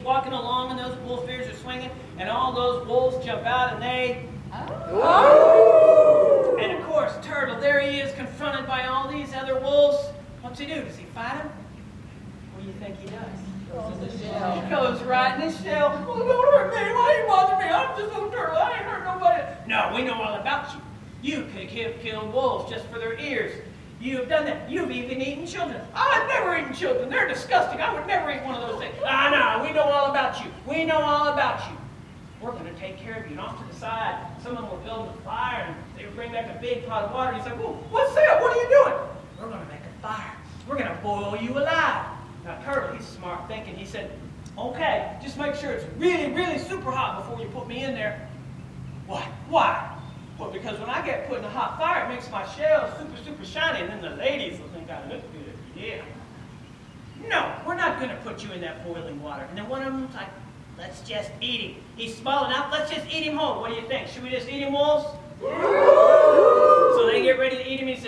The audience hoooowwwwls during a turtle story